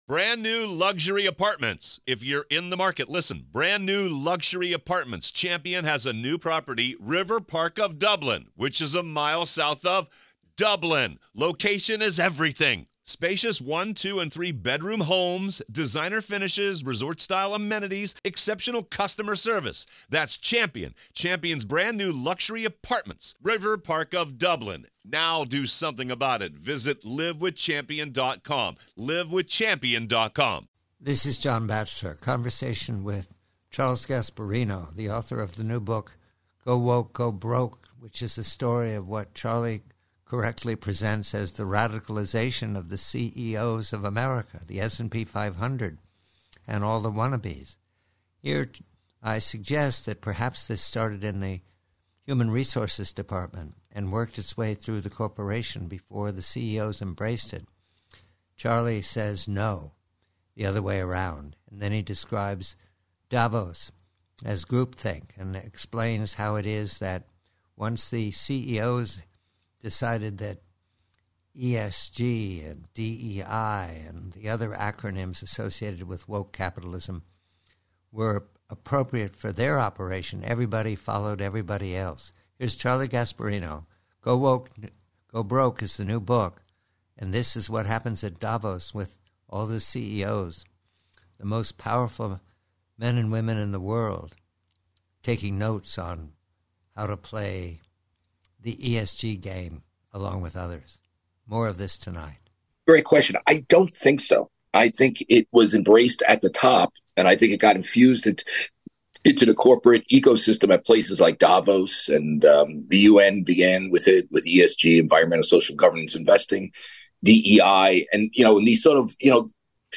PREVIEW: ESG, DEI & CEO: Conversation with Charles Gasparino of Fox Business re his new book, GO WOKE GO BROKE, how the CEOs of the S&P 500 and more came to mandate ESG and DEI in their companies -- and how the Davos Men and Women were leaders in such groupthink.